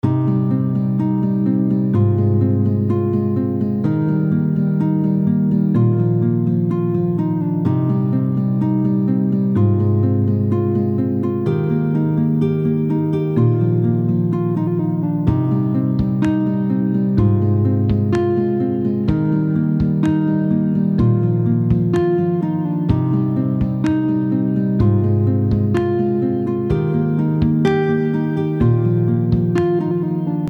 à la guitare
Audio de la tablature complète :
Accordage : Standard